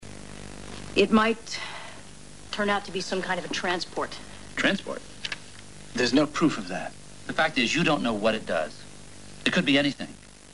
these people talking about a 'transport'?